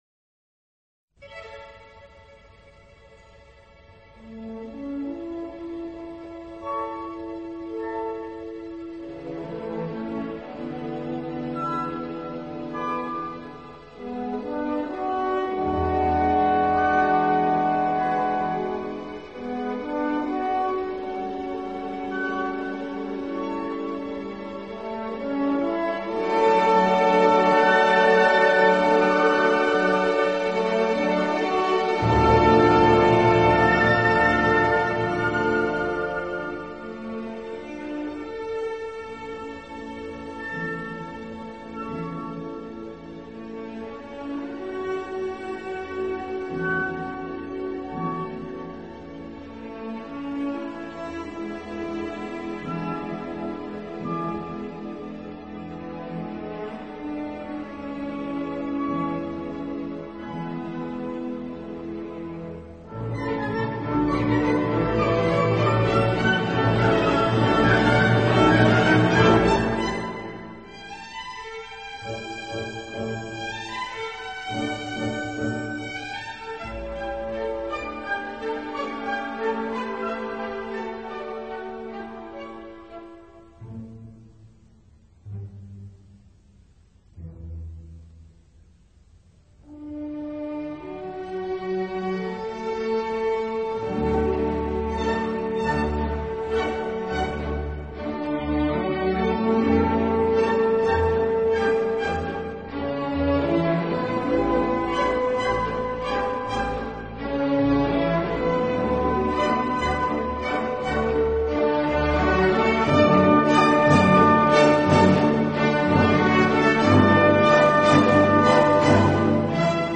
音乐类型：Classic 古典
音乐风格：Classical,Waltz